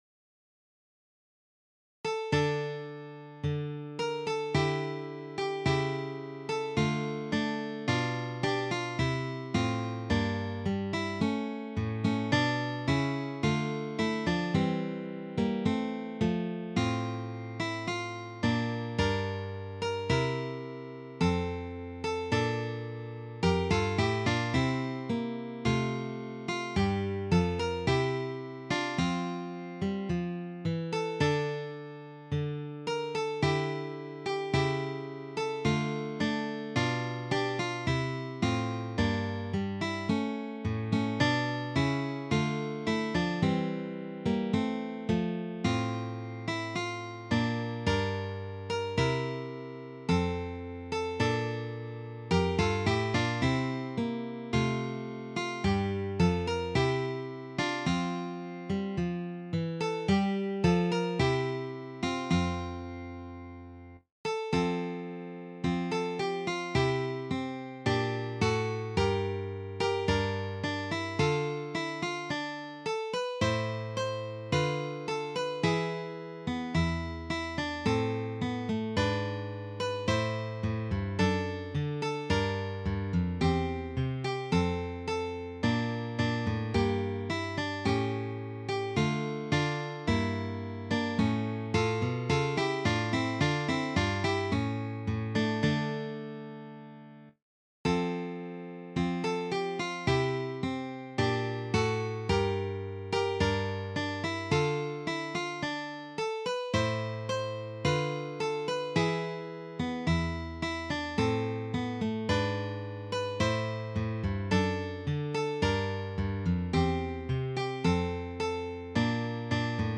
arranged for three guitars
This Baroque selection is arranged for guitar trio.